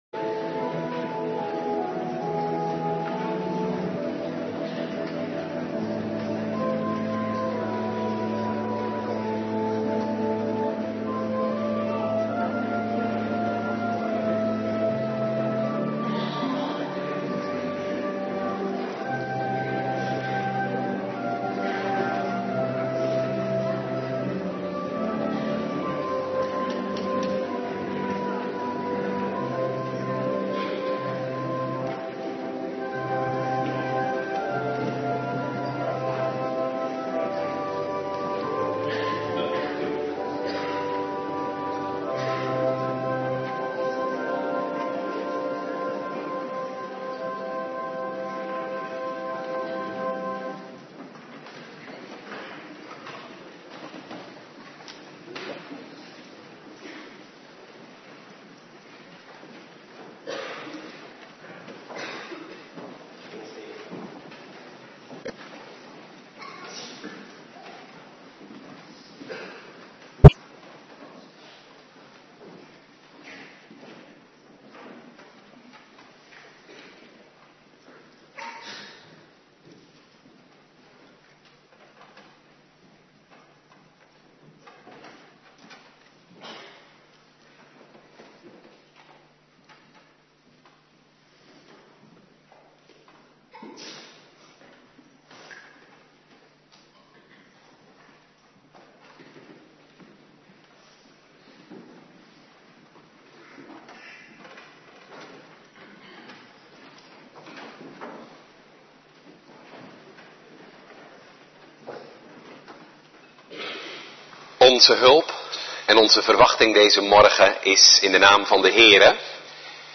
Morgendienst